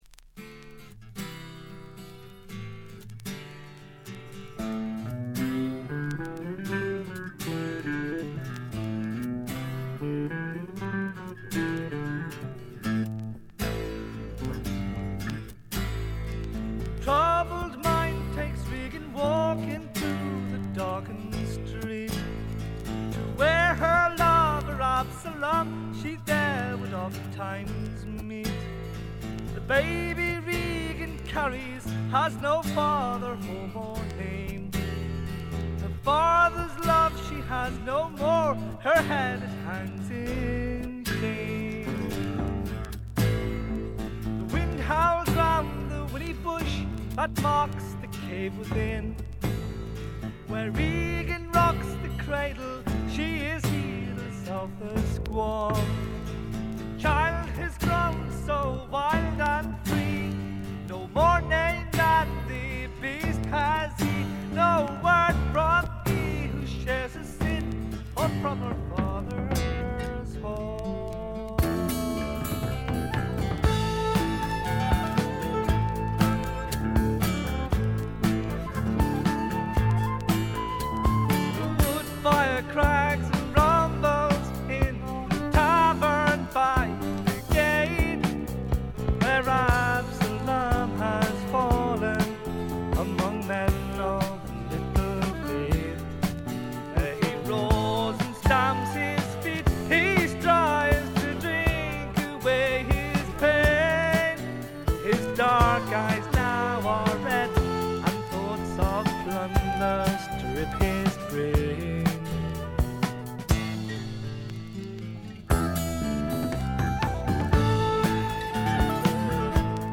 静音部で軽微なチリプチが聴かれますが鑑賞に影響するようなノイズはありません。
ドラムとベースがびしばし決まるウルトラグレートなフォーク・ロックです。
試聴曲は現品からの取り込み音源です。